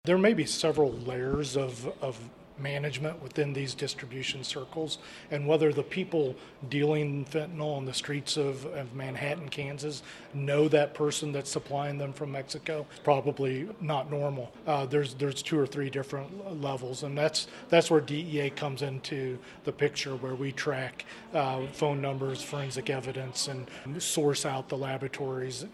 On Wednesday, leadership from RCPD, the Kansas Highway Patrol and Drug Enforcement Administration gathered at the Riley County Attorney’s Office building to announce four more arrests as part of their ongoing investigation.